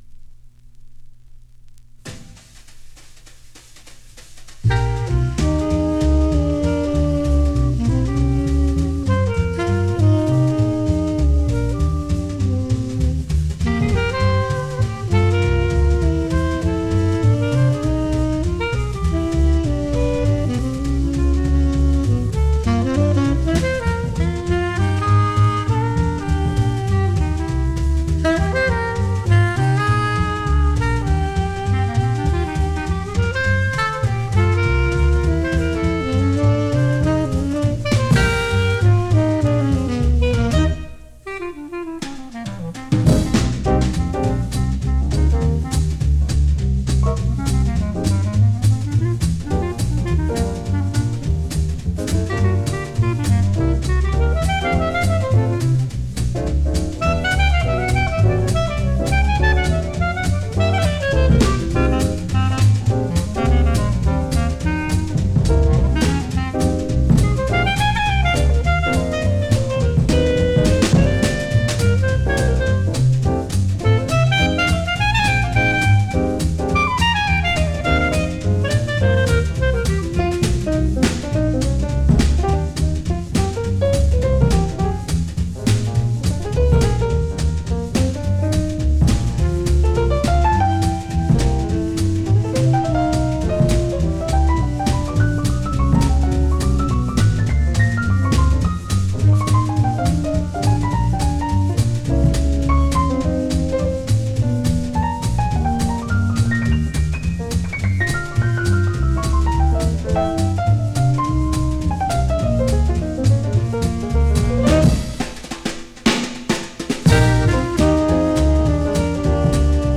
Recorded: 1960 in Sydney, Australia
is a catchy little tune